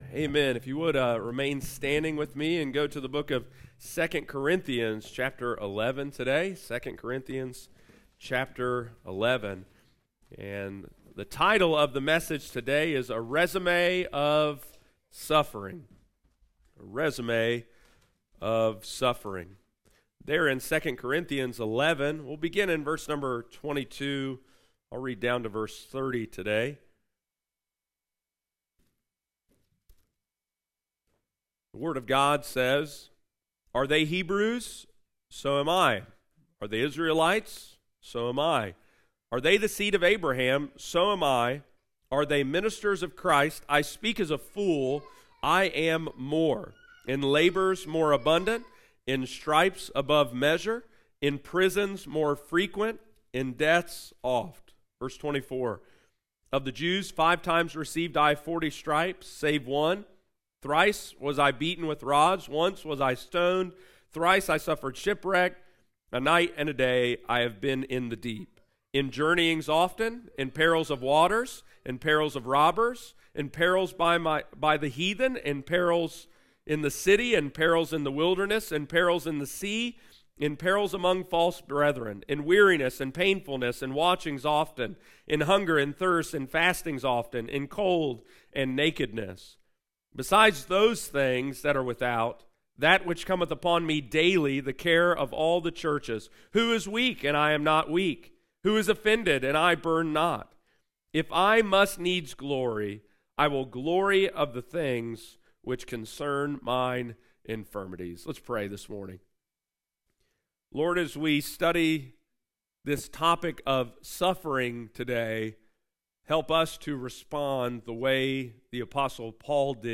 Resume Of Suffering – Lighthouse Baptist Church, Circleville Ohio